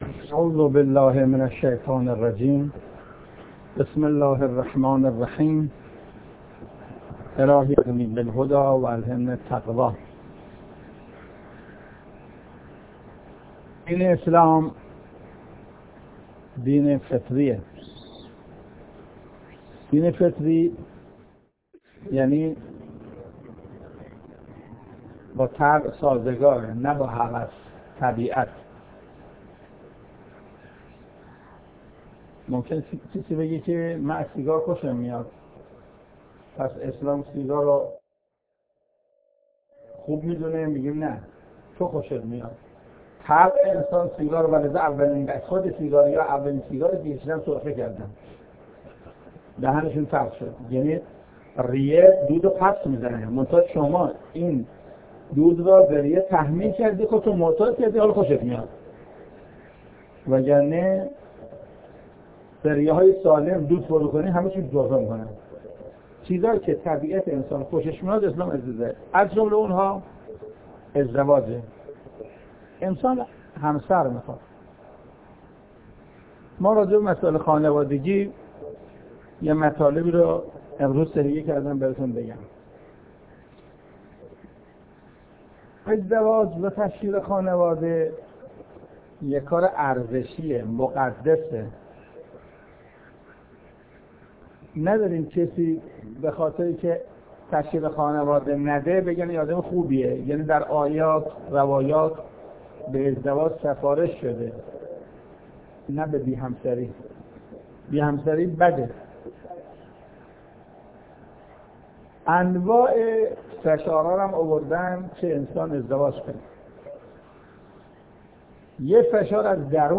دومین سخنرانی حجت الاسلام والمسلمین محسن قرائتی با مضمون جایگاه ازدواج در قرآن کریم منتشر می شود.